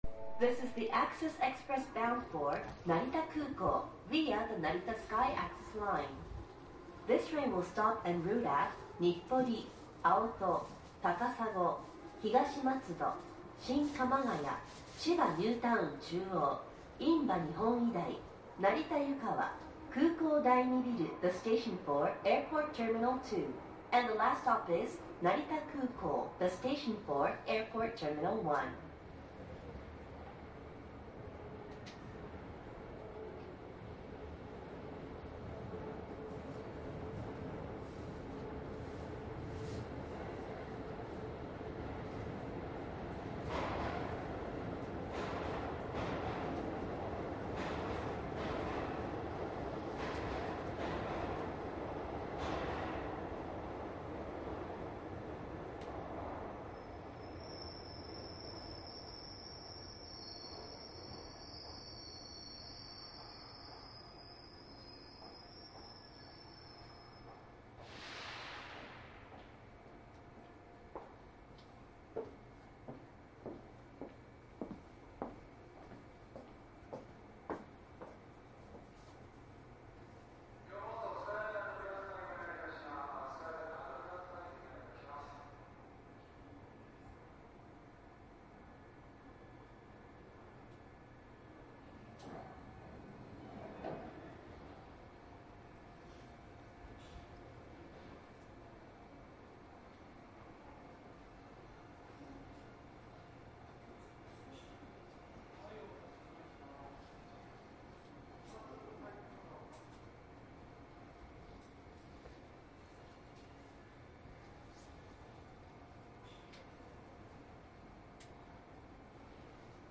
商品説明北総線 京成3050系 鉄道走行音 ＣＤ ♪
アクセス特急を録音した ＣＤです。
マスター音源はデジタル44.1kHz16ビット（マイクＥＣＭ959C）で、これを編集ソフトでＣＤに焼いたものです。
収録された音源の車内における乗客の会話などはほとんどありません。